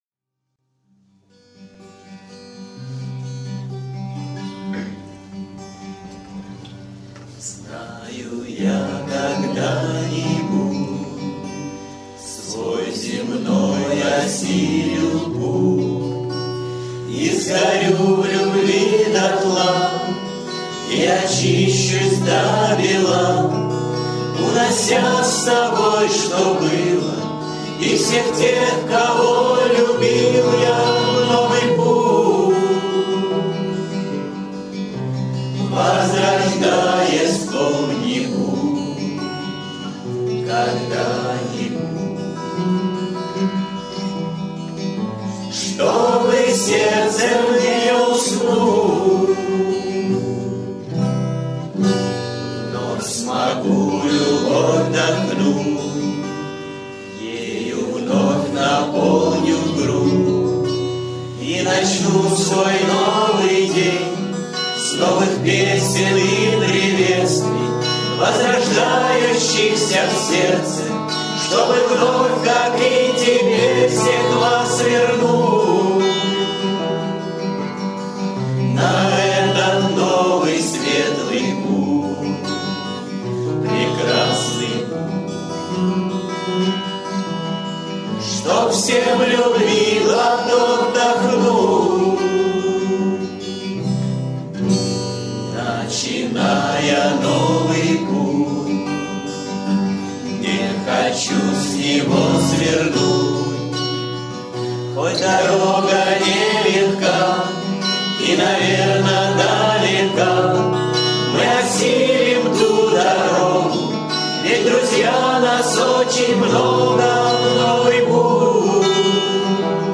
кавер-версия
Песни у костра